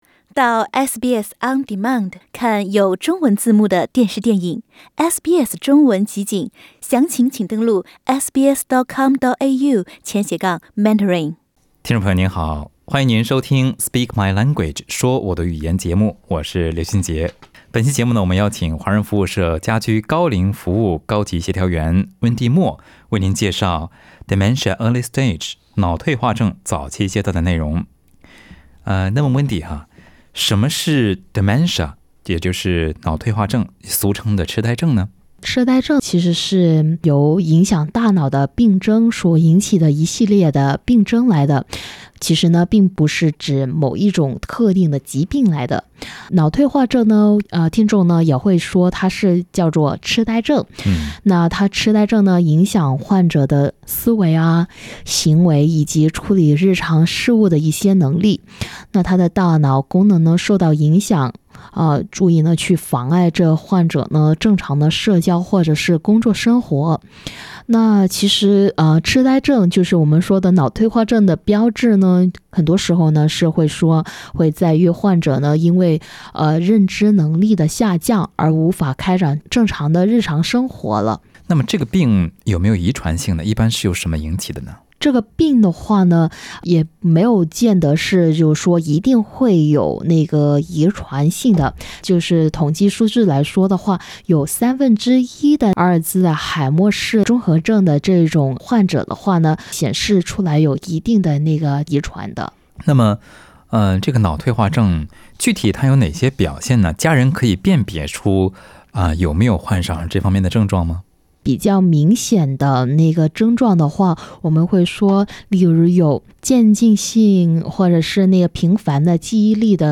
Speak My Language: Conversations about ageing well Source: Ethnic Communities Council NSW